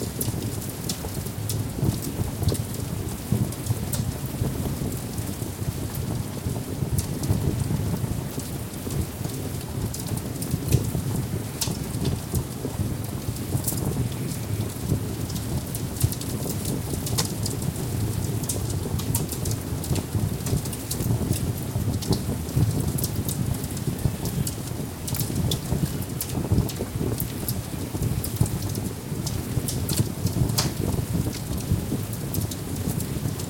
FireplaceLoop.wav